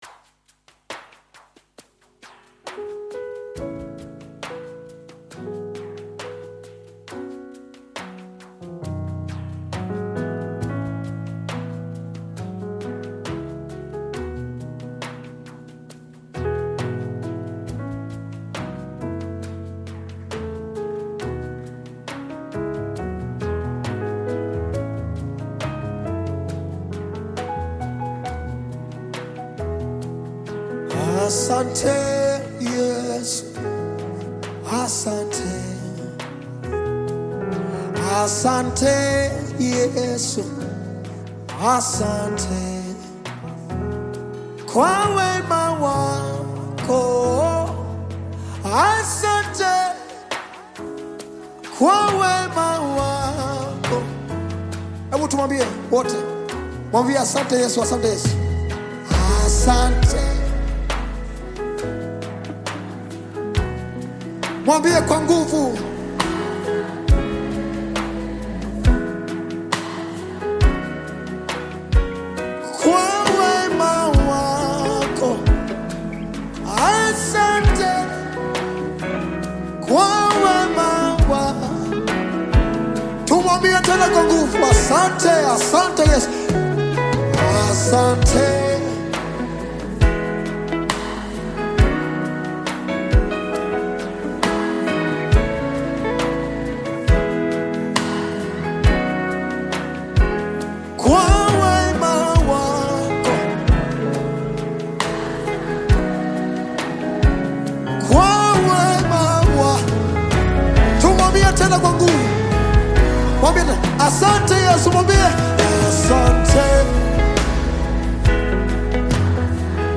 Nyimbo za Dini Thanksgiving music
Thanksgiving Gospel music track